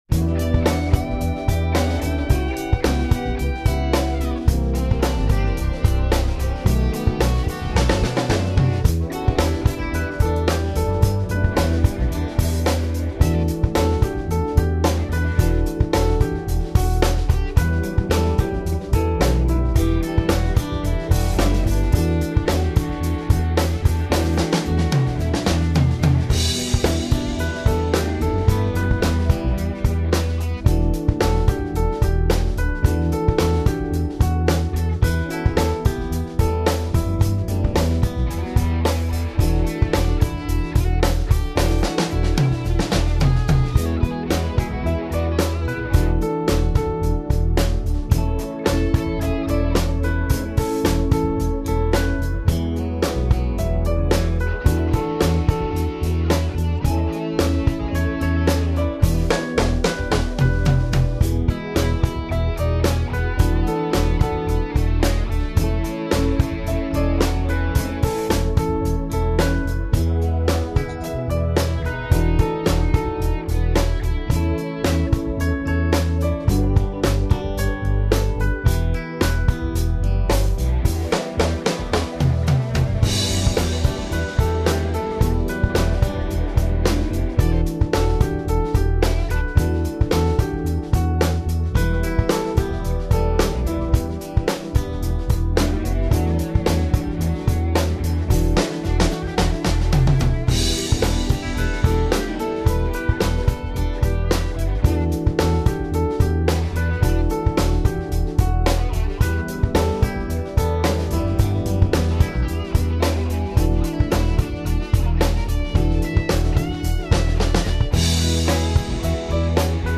This is a song of praise